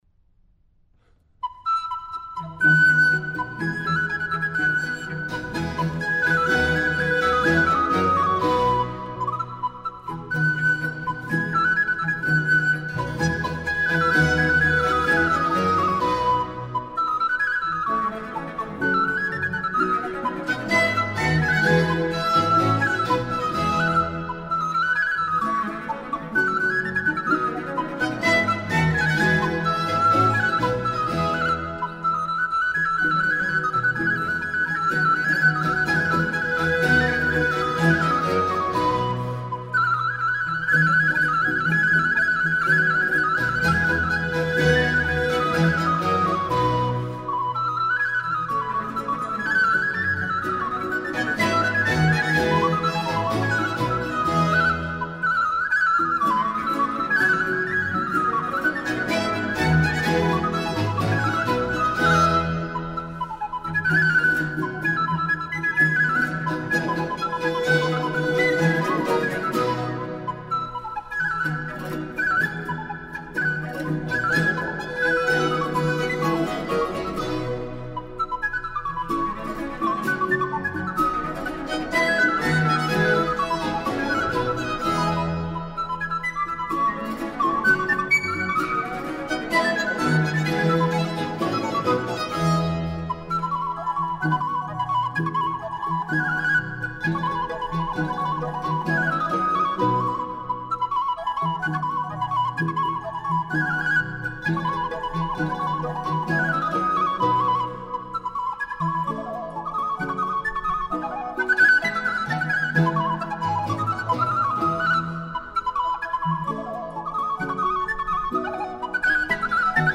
Baroque.
V. Gavotta. Allegro.